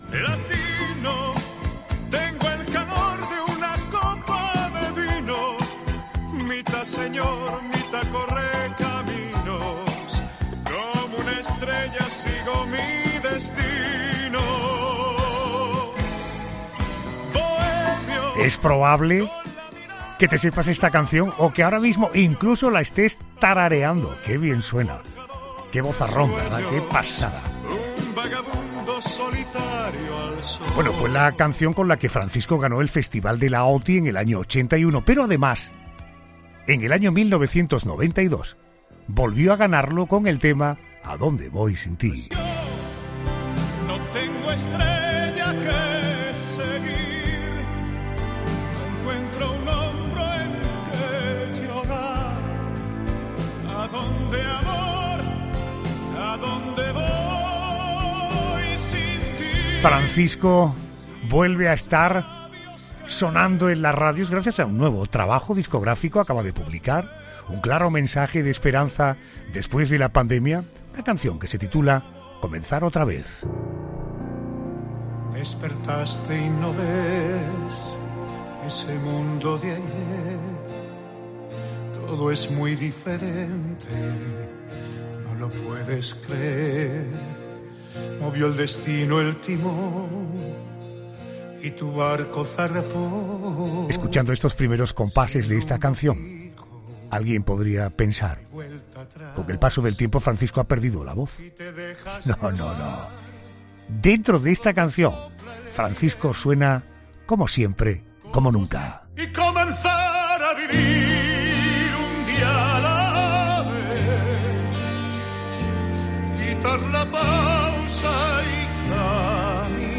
Francisco ha contado en La Noche de Adolfo Arjona que tiene ganas de seguir trabajando pero que algunos se lo impiden.